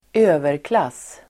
Ladda ner uttalet
Uttal: [²'ö:verklas:]